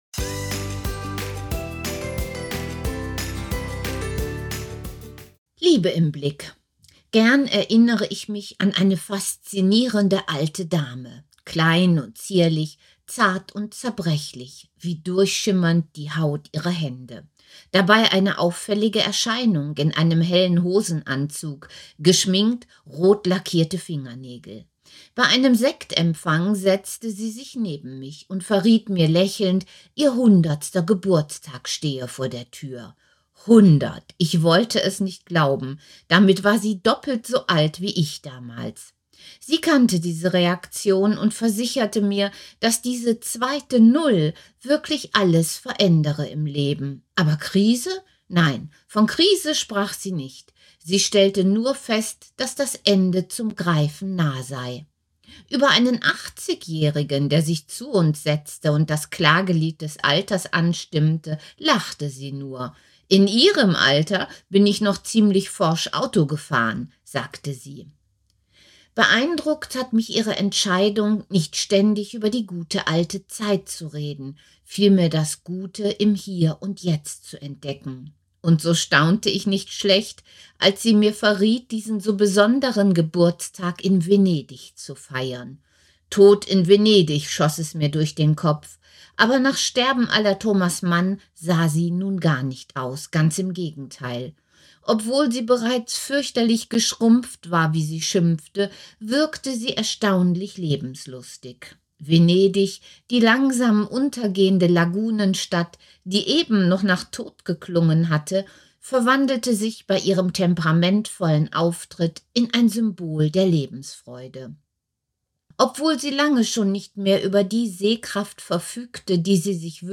Text als Audiodatei